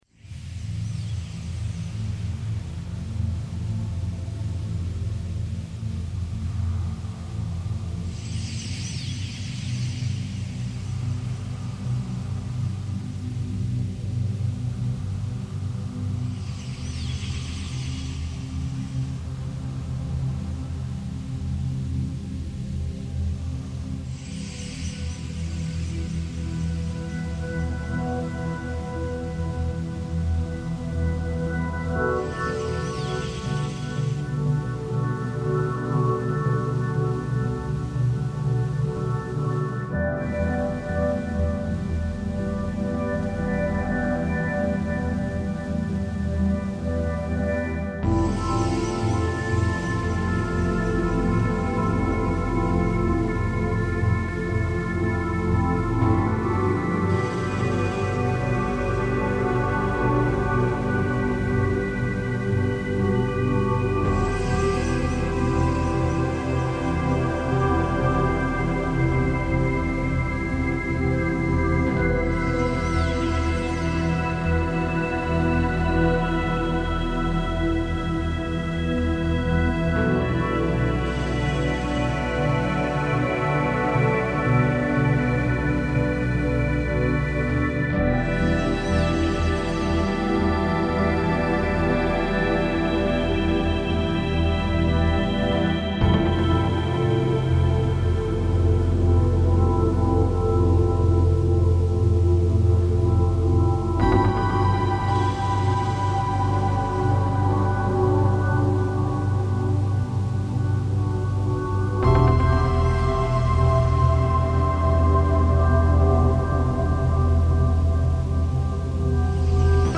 Starling murmuration in Curtarolo, Italy reimagined